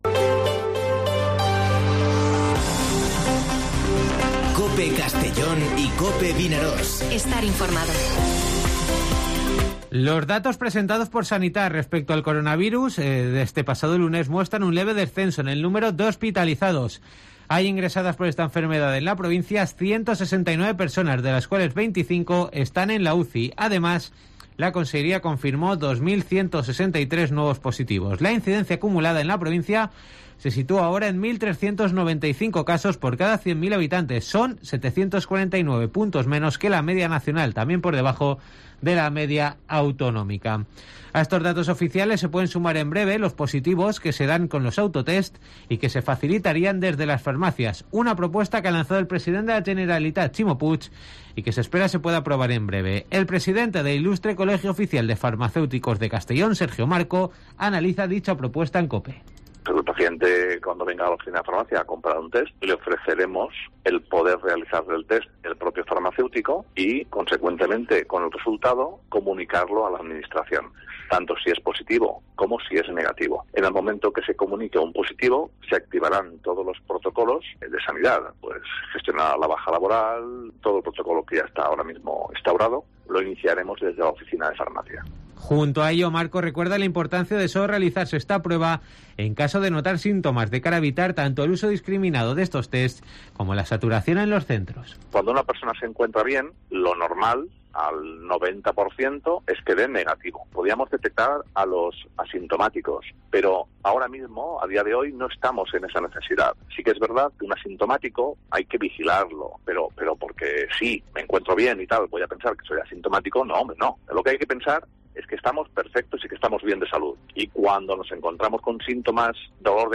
Informativo Mediodía COPE en Castellón (04/01/2022)